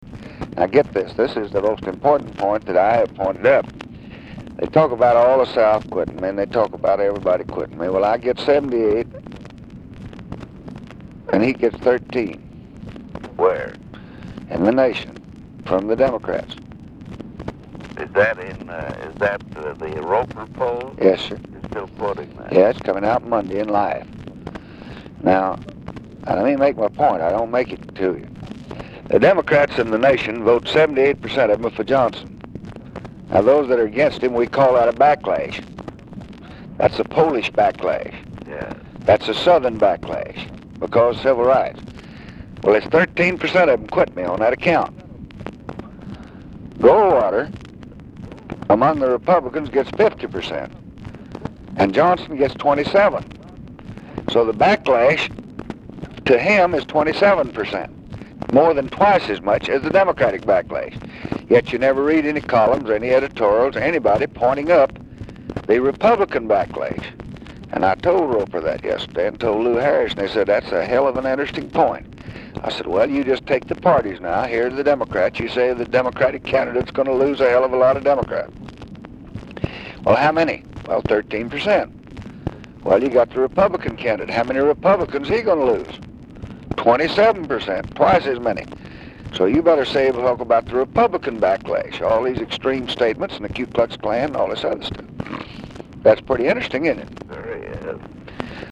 LBJ’s hope that the election could achieve a realignment by winning over the “frontlash” voters—Republicans disaffected by Goldwater’s stances on civil rights and nuclear issues—was the President’s key political aim in the campaign. He used polls to focus on the concept throughout the summer, as in this early August clip with advisor